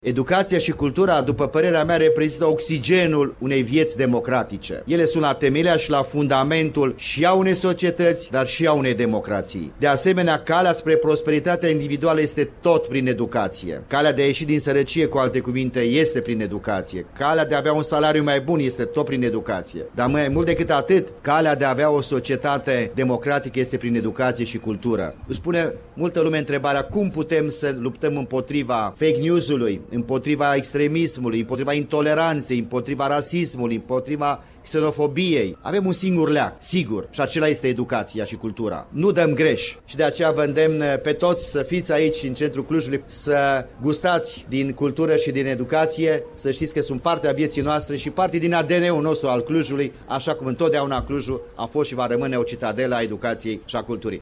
Primarul municipiului Cluj-Napoca, Emil Boc, prezent la deschiderea oficială, a declarat că acest târg este o pledoarie pentru educație, pentru cultură, pentru democrație și pentru identitate europeană: